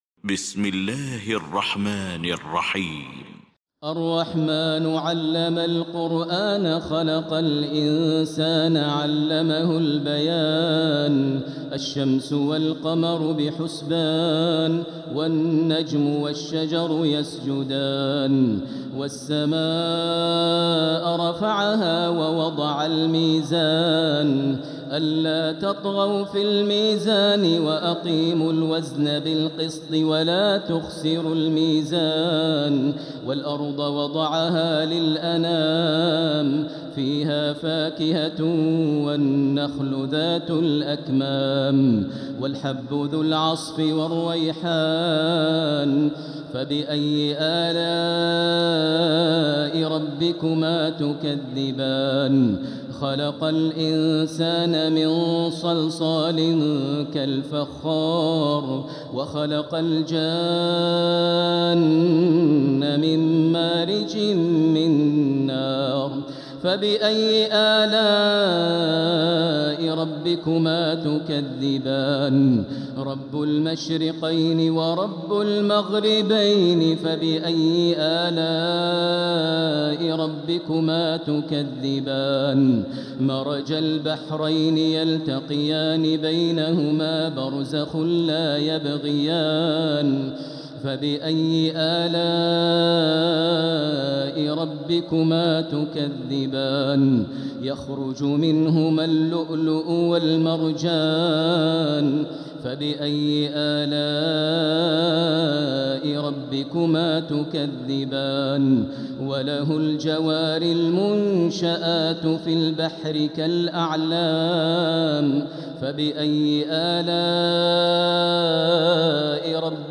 المكان: المسجد الحرام الشيخ: فضيلة الشيخ ماهر المعيقلي فضيلة الشيخ ماهر المعيقلي الرحمن